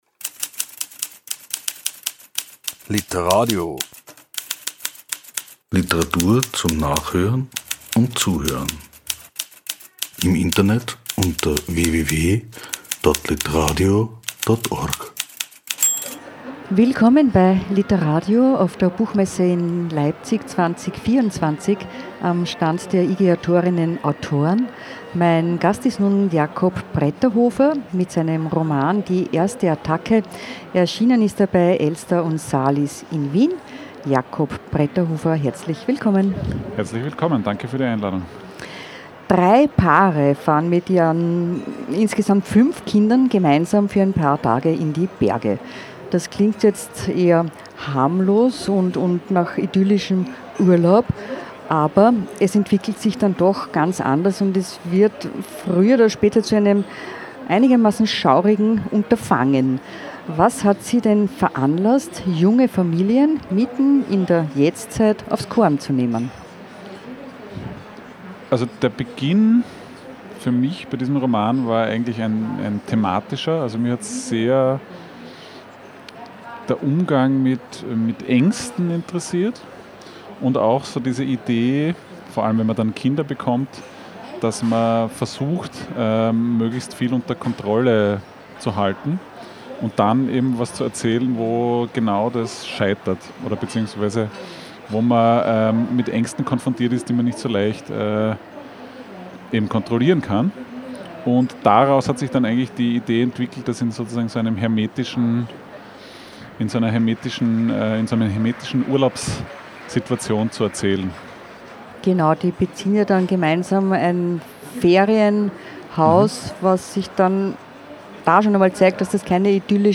Der Autor im Gespräch mit